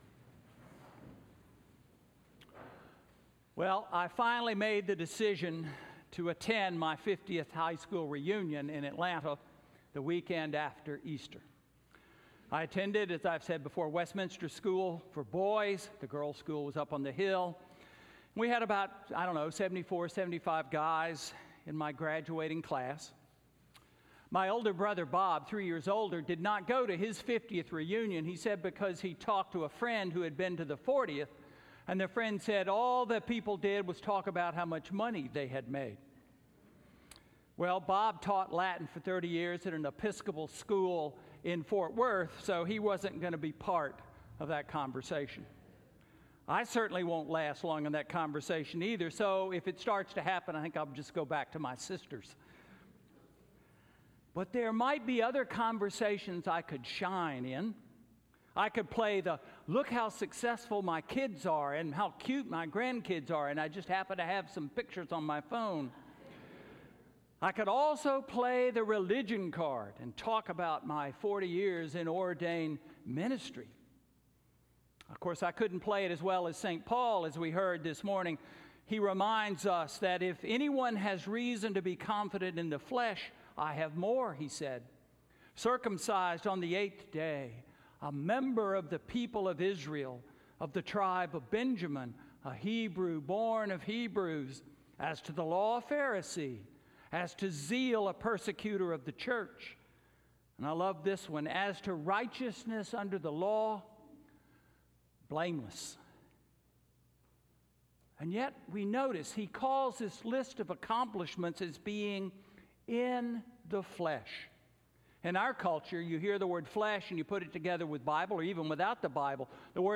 Sermon–Mud Pies or a Trip to the Sea?–April 7, 2019